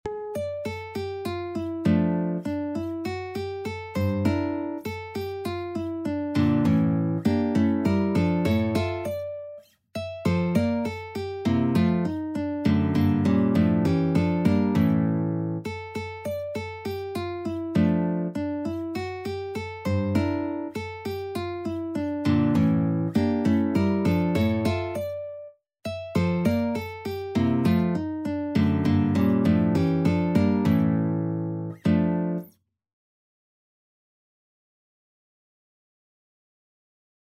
Guitar version
Guitar  (View more Intermediate Guitar Music)
Classical (View more Classical Guitar Music)